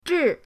zhi4.mp3